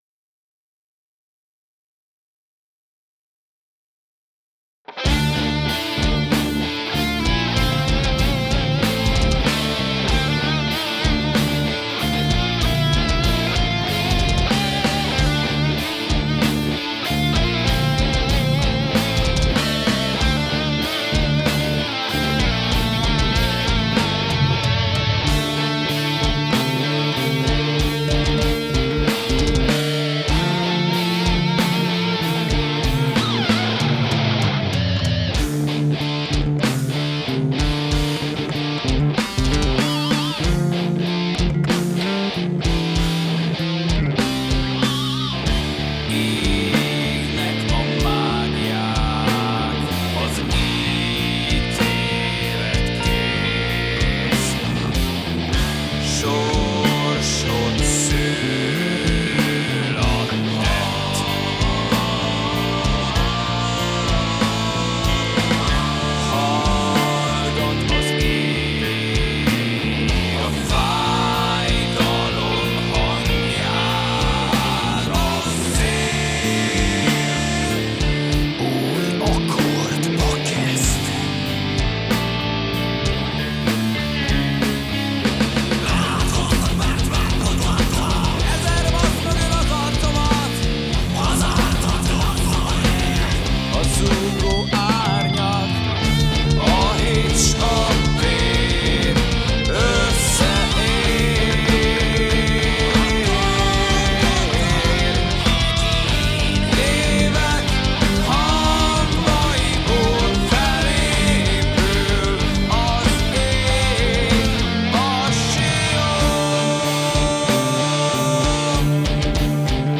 gitár
basszusgitár